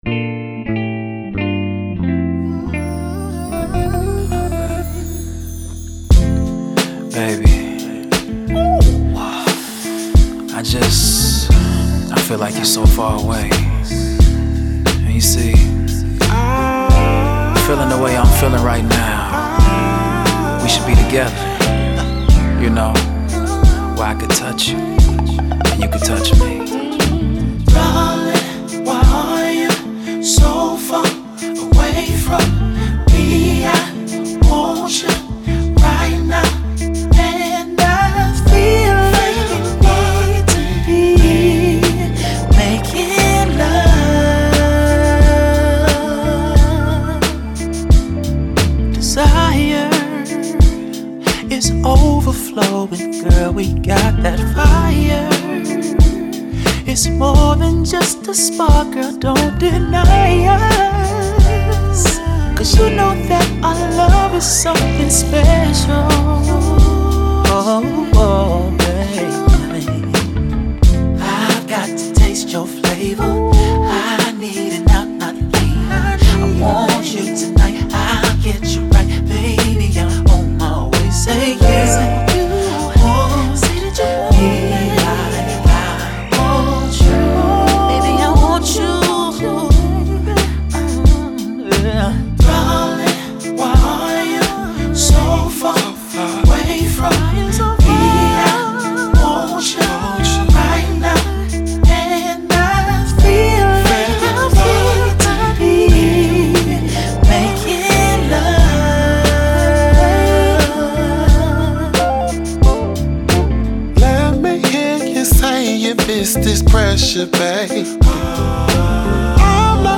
soulful R&B/gospel/hiphop influenced sound of the Midwest
smooth melodies, heartfelt lyrics